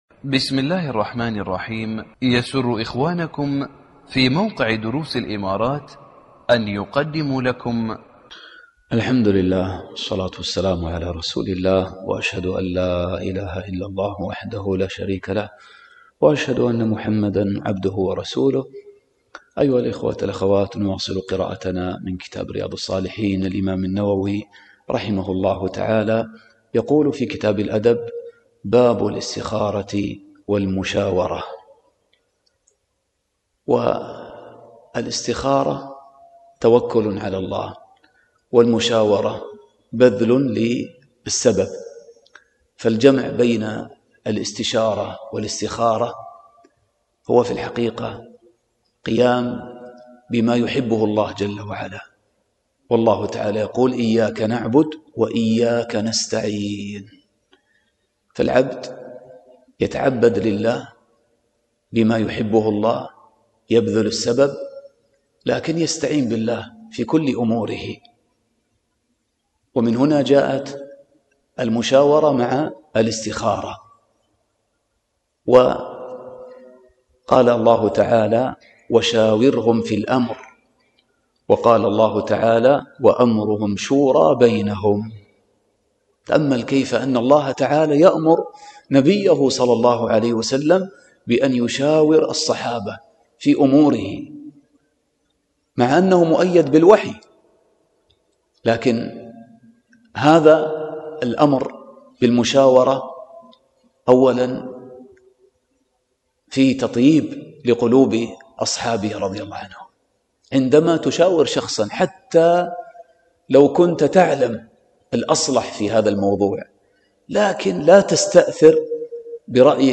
الدرس 77